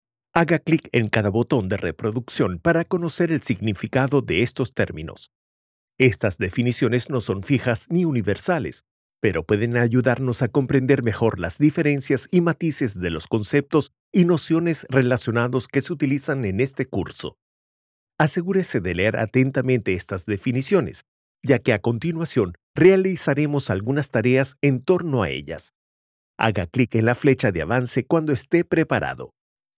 Commercieel, Jong, Stoer, Veelzijdig, Zakelijk
Explainer